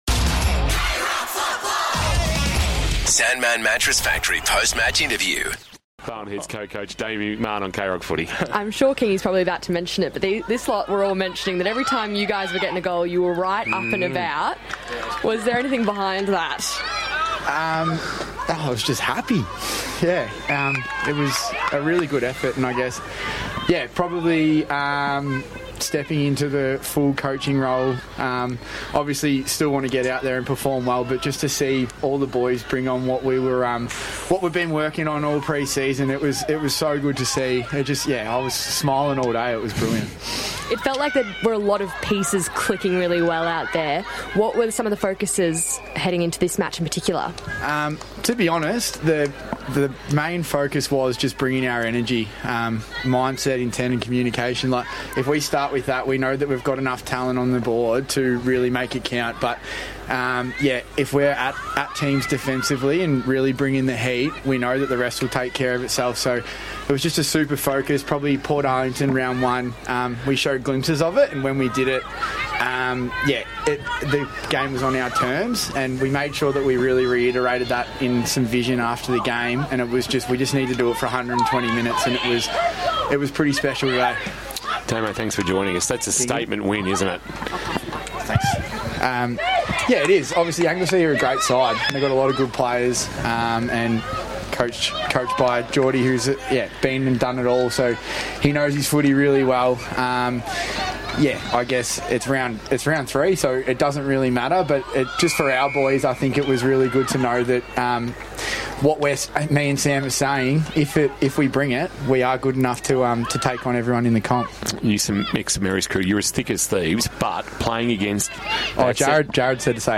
2026 - BFNL - Round 3 - Barwon Heads vs. Anglesea - Post-match interview